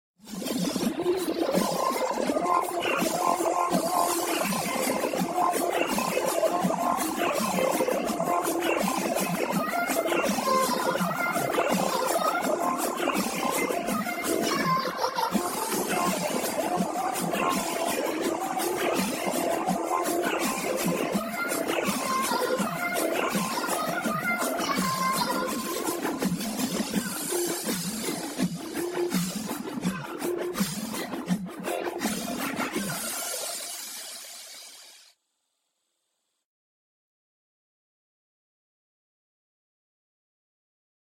Trance cover